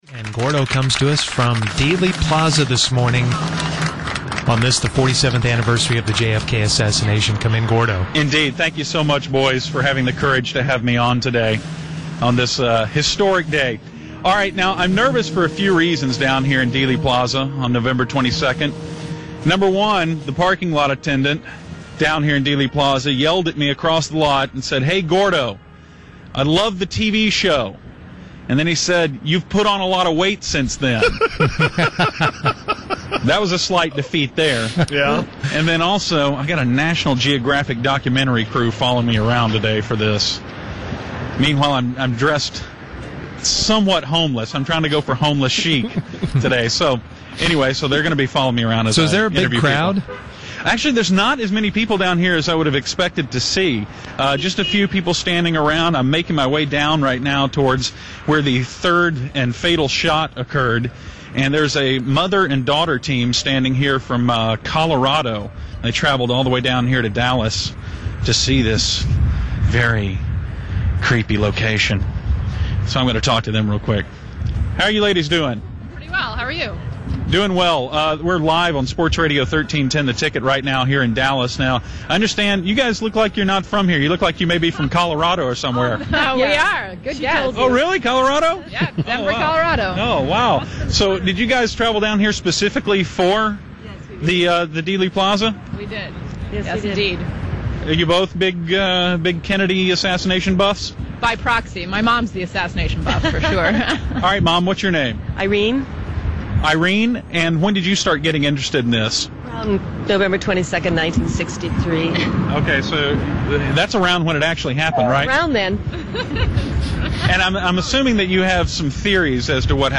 While he was there, he interviewed a few conspiracy theorists.
All he really had to do was put a microphone in people’s face.